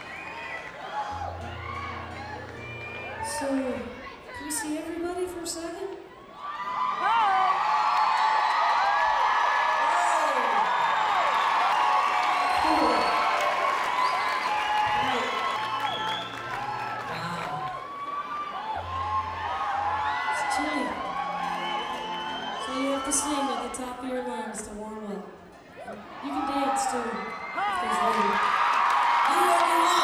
lifeblood: bootlegs: 1994-07-03: stratton mountain - stratton, vermont
(acoustic duo show)
04. talking with the crowd (0:30)